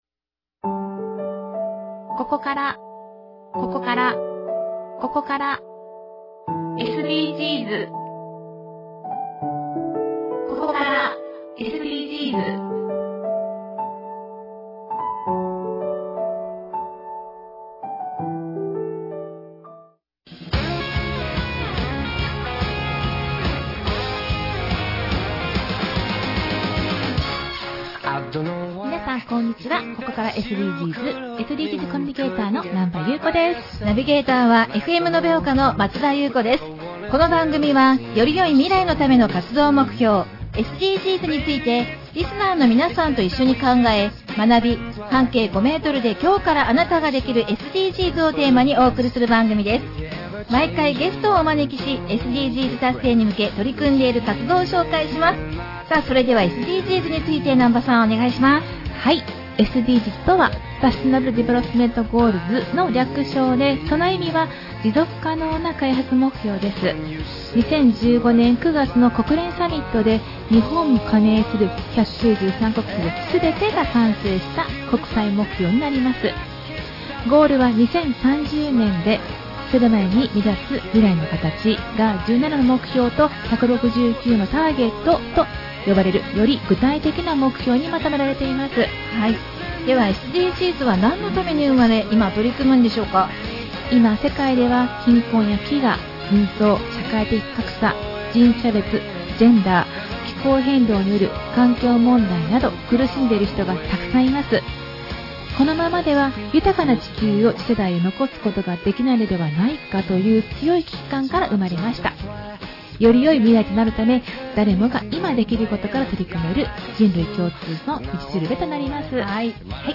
特別番組